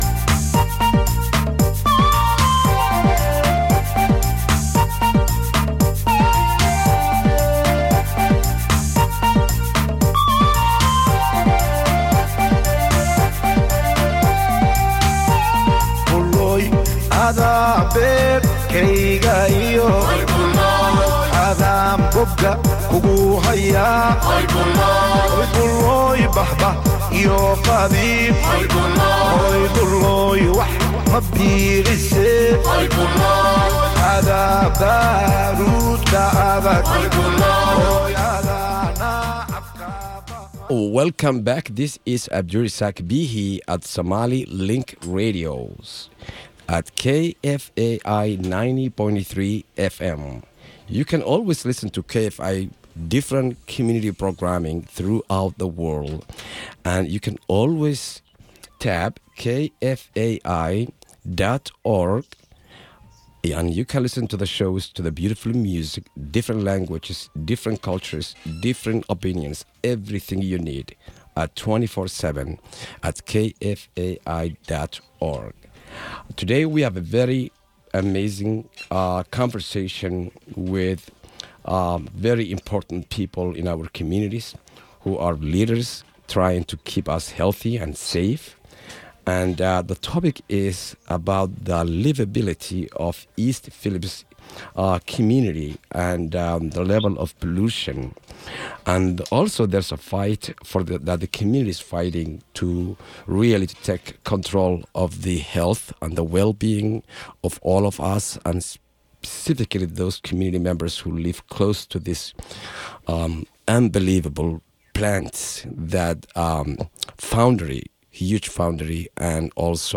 Interviews center the stories of mothers, the Indigenous community, and local leaders and discuss ways an urban farm could create job opportunities and reduce food insecurity in the neighborhood.